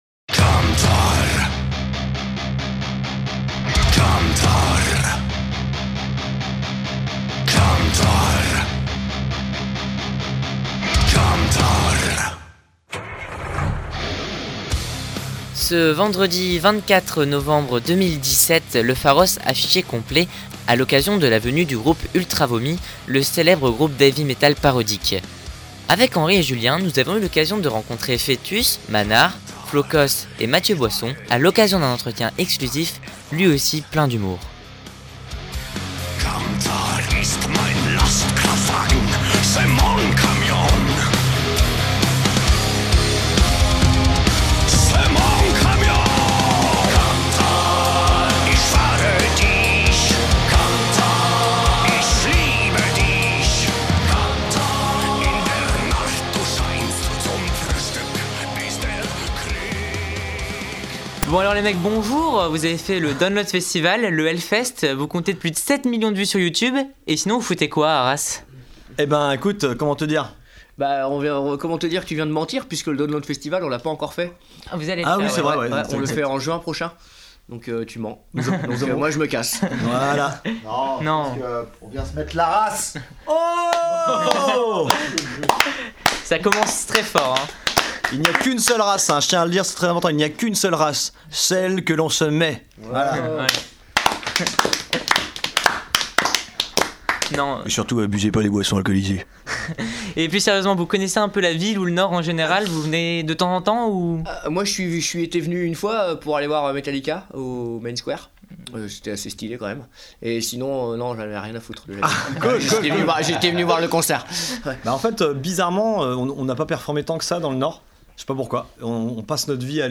Nous avons eu l’occasion de rencontrer ses membres, pour un entretien exclusif rempli d’un humour décapant. Une interview exclusive pour Radio PFM, 99.9